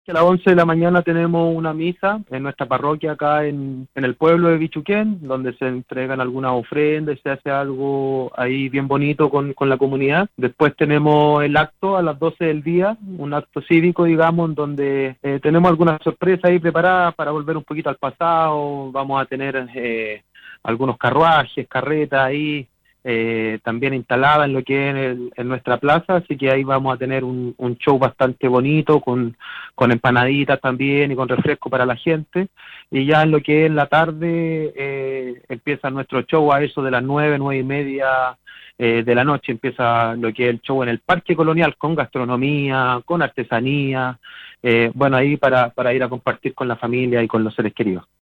Lo anterior, fue detallado por el alcalde Patricio Rivera (IND) en conversación con el programa "Sala de Prensa" de VLN Radio (105.7 fm).